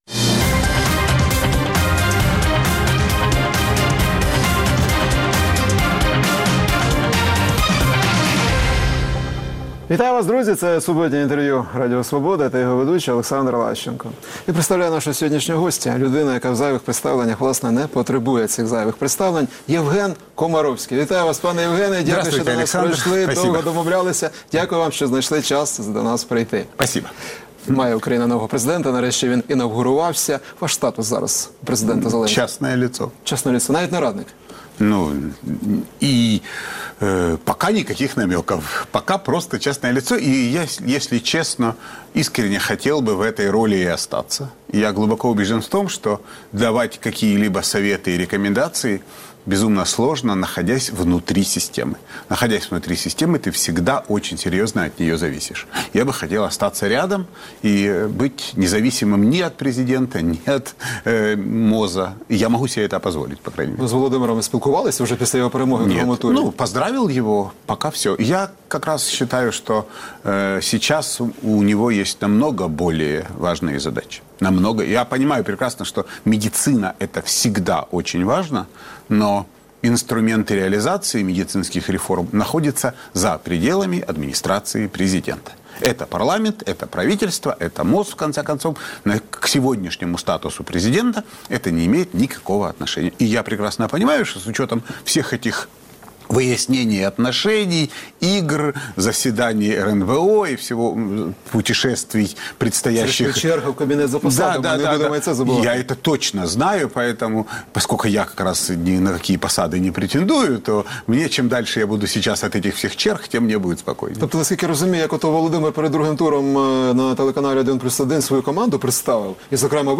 Суботнє інтерв’ю | Євген Комаровський, лікар і телеведучий